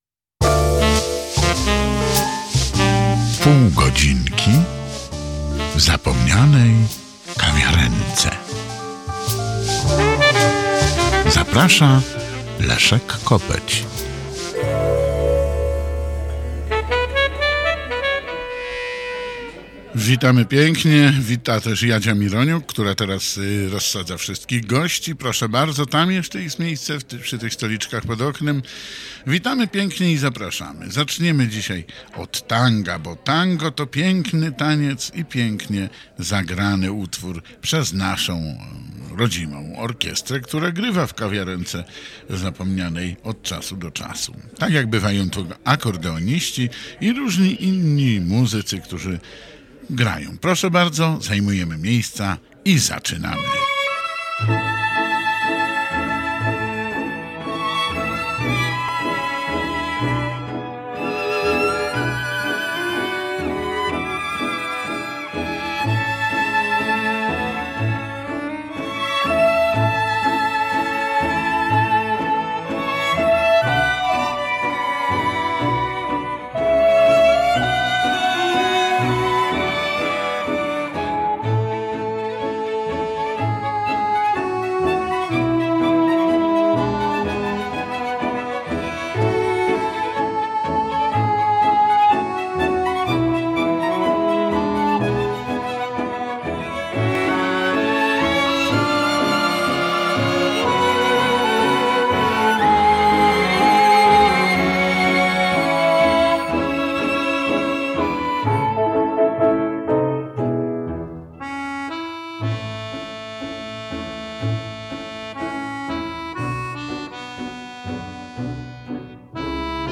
Prezentowane są polskie piosenki z lat: 30,50, 60 ubiegłego stulecia.